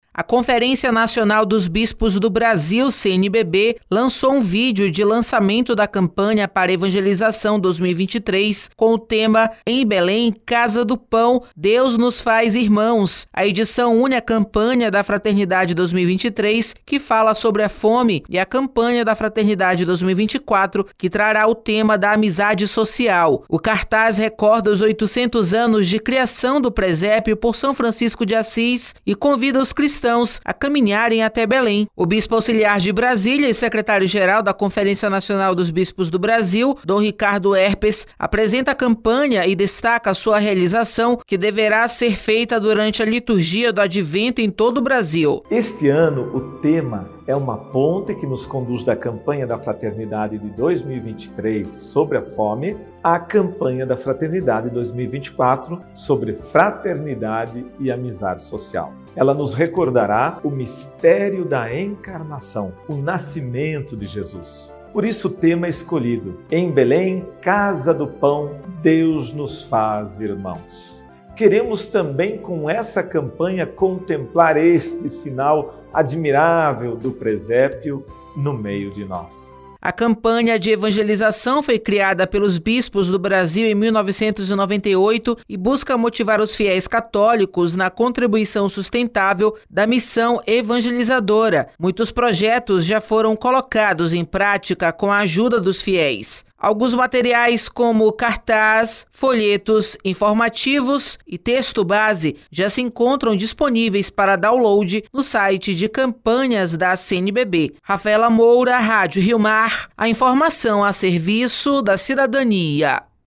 O bispo auxiliar de Brasília (DF) e secretário-geral da Conferência Nacional dos Bispos do Brasil (CNBB), dom Ricardo Hoepers, apresenta a campanha e destaca a sua a realização que severa ser feita durante a liturgia do advento em todo o Brasil.